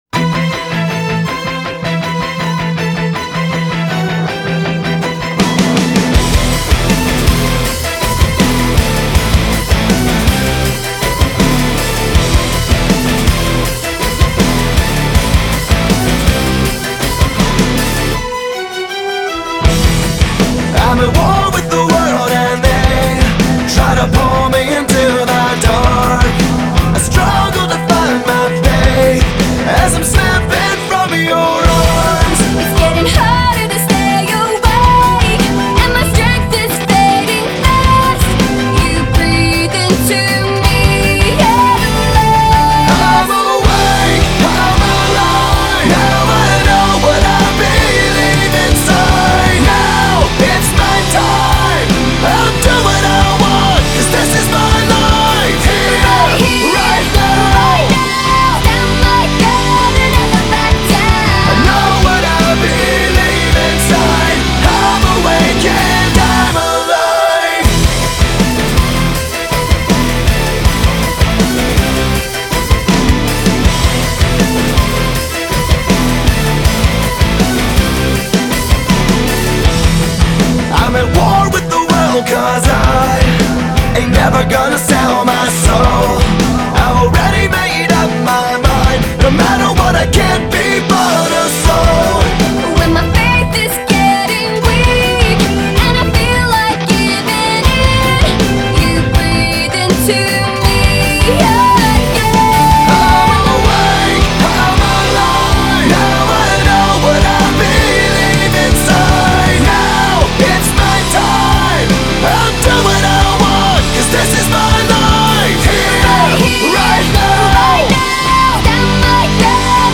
Категория: Альтернатива